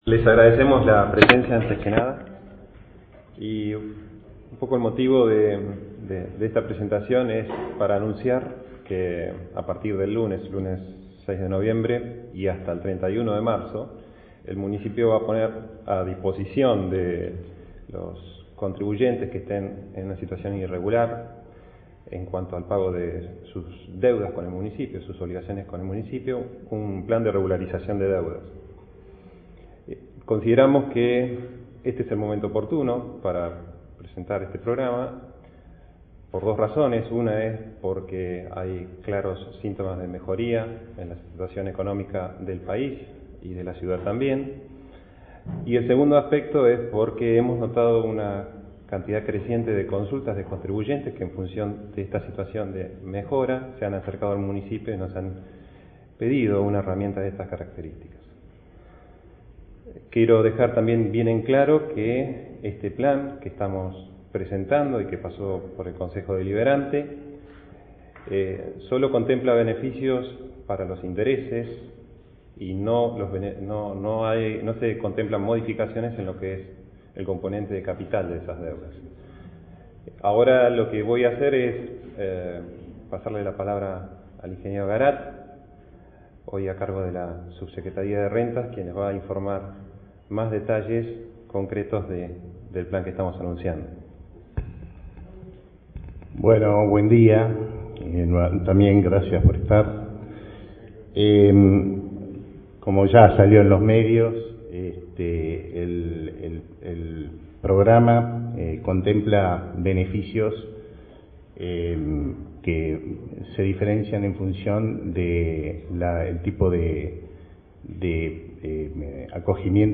El secretario de Hacienda y Desarrollo Económico, Juan Esandi, brindó detalles del plan de regularización espontánea de obligaciones tributarias.